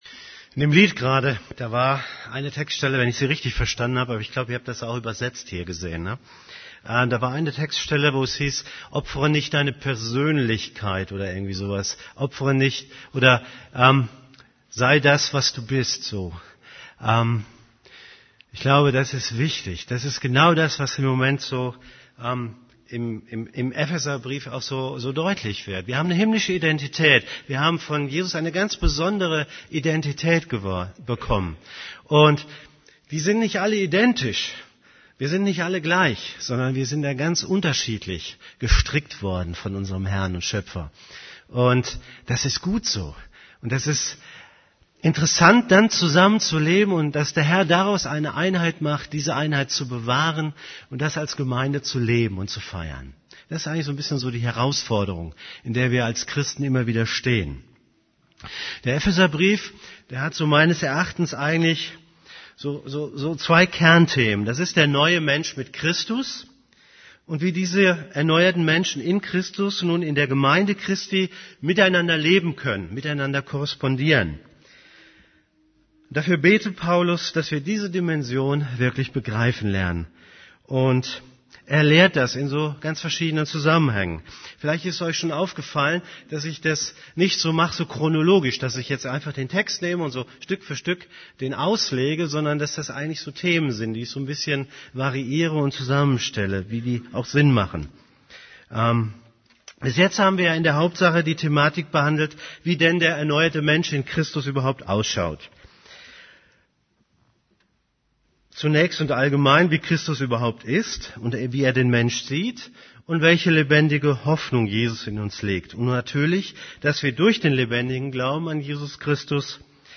> Übersicht Predigten Unsere Verantwortung als Christ Predigt vom 09.